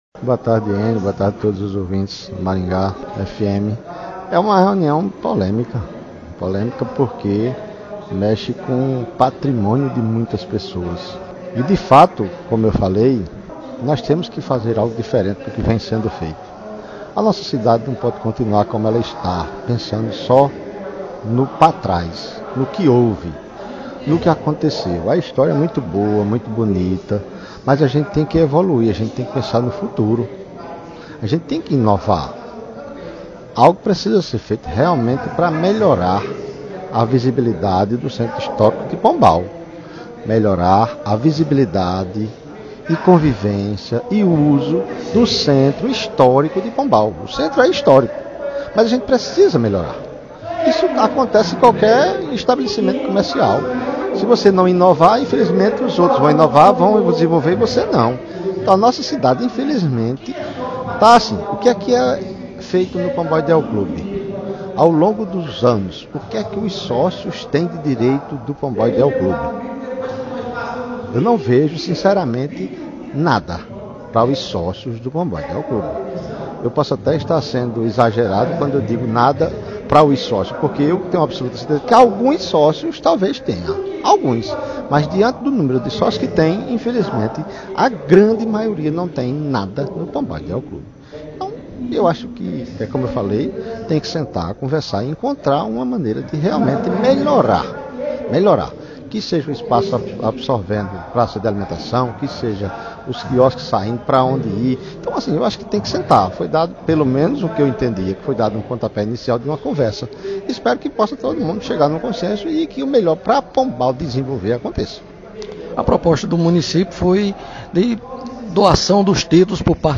Nossa reportagem ouviu ainda o vereador Edno Dantas, que também é sócio e falou sobre seu ponto de vista.